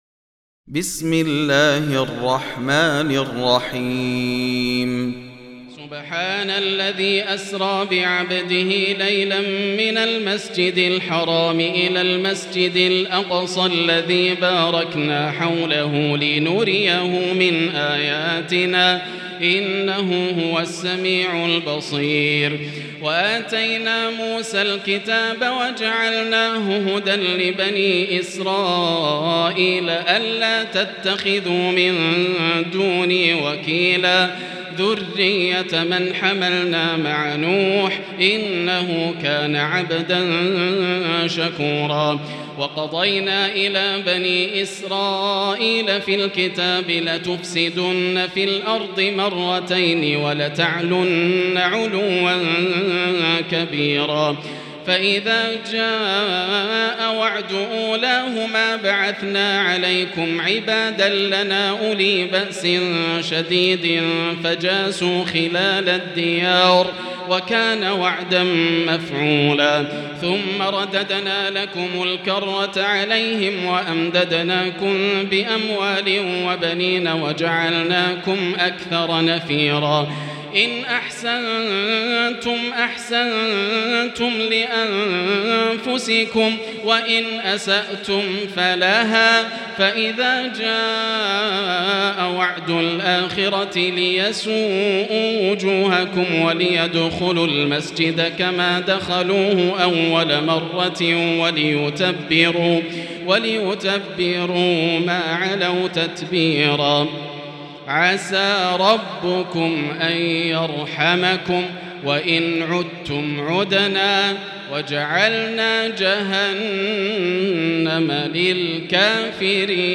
المكان: المسجد الحرام الشيخ: معالي الشيخ أ.د. عبدالرحمن بن عبدالعزيز السديس معالي الشيخ أ.د. عبدالرحمن بن عبدالعزيز السديس فضيلة الشيخ عبدالله الجهني فضيلة الشيخ ياسر الدوسري الإسراء